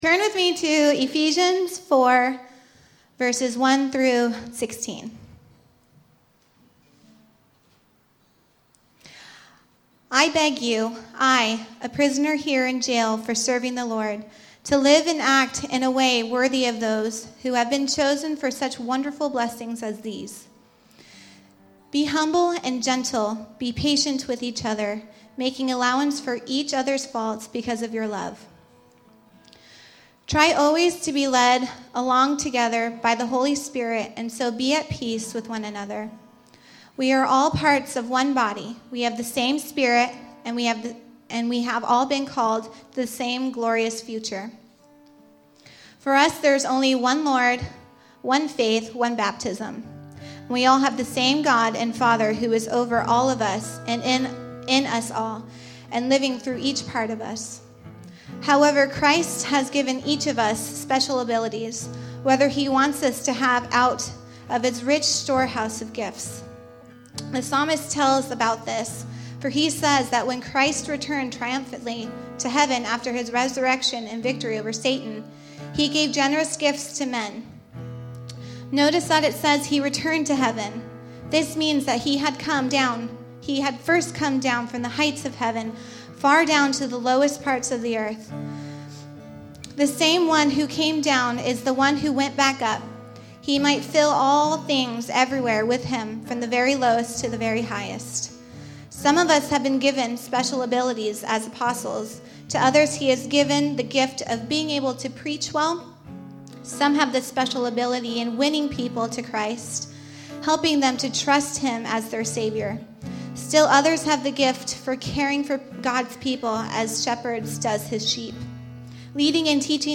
Deacon Sermon Series